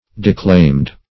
Declaimed (d[-e]*kl[=a]md"); p. pr. & vb. n. Declaiming.]